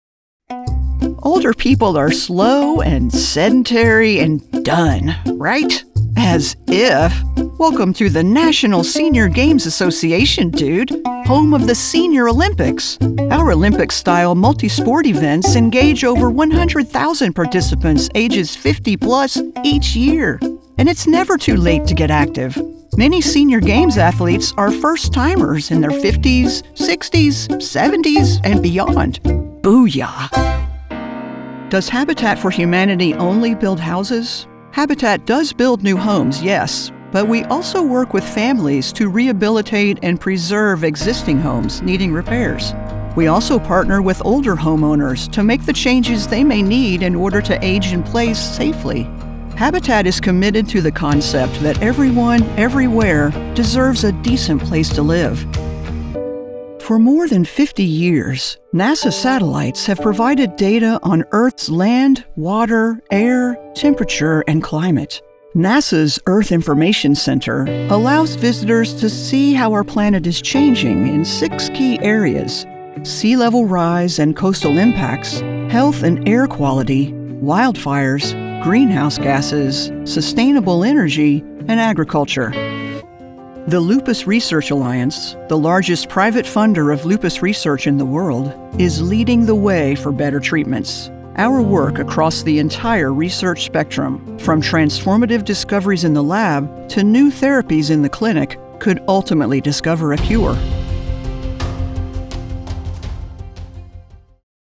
Corporate Narration
English - Southern U.S. English
Appalachian, Southern Georgia coast
Middle Aged